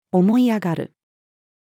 思い上がる-female.mp3